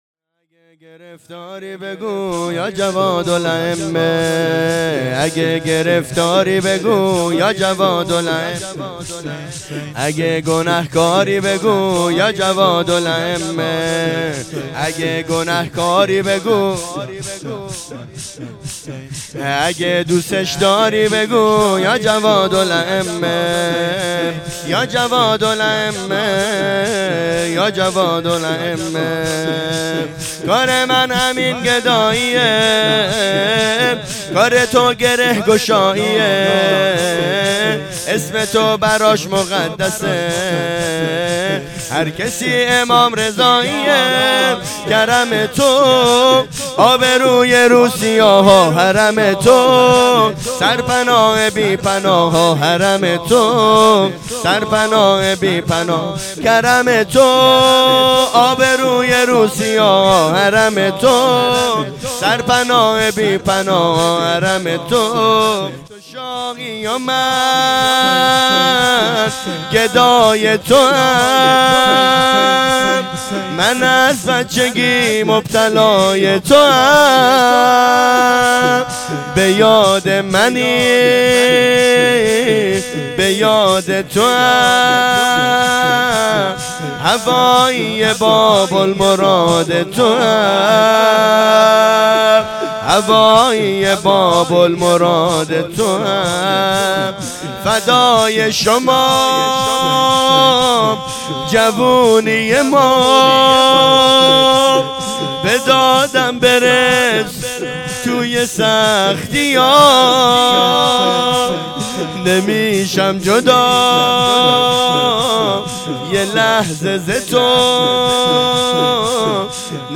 شهادت امام جواد علیه السلام 1404